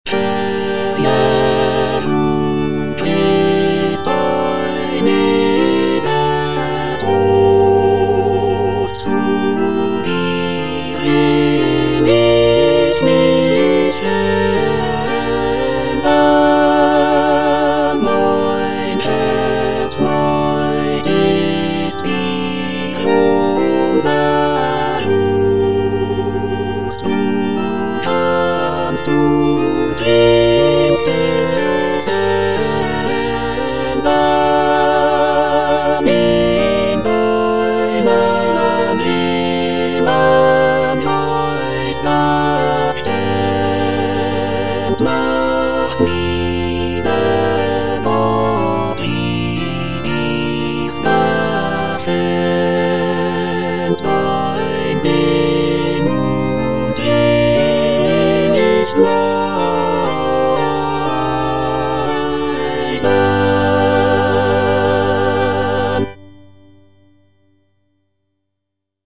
Choralplayer playing Cantata
(All voices)